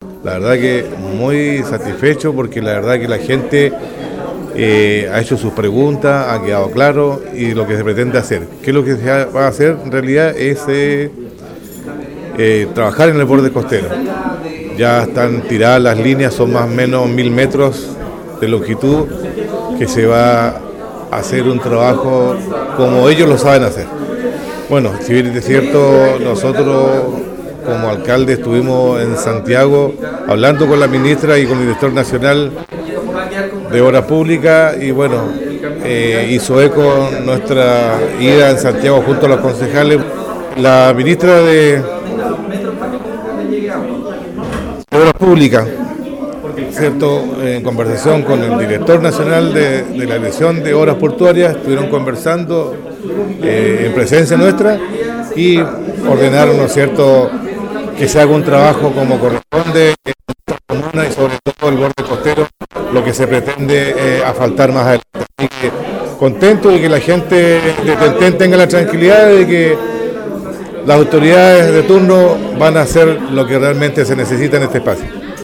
En una reunión ampliada con los vecinos del sector de Ten-Ten, se dio cuenta de los detalles de la iniciativa que no estaba dentro del proyecto de asfaltado pero que se logró fruto de intensas gestiones en Santiago por parte de la máxima autoridad comunal.
En la ocasión el alcalde Elgueta, señaló:
Alcalde-por-anuncios-en-ten-ten.mp3